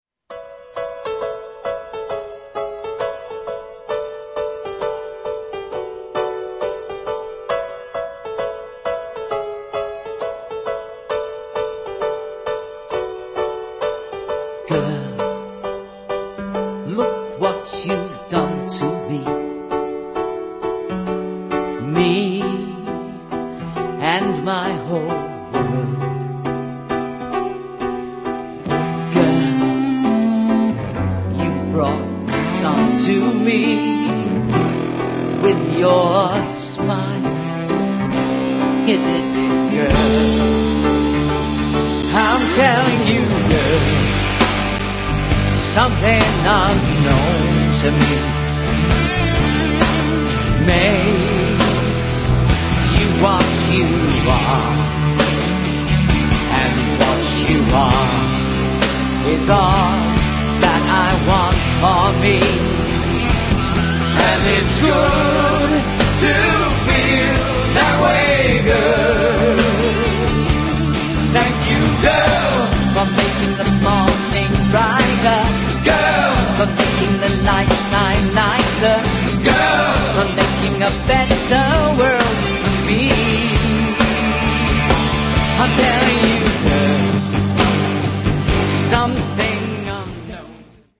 Sitcom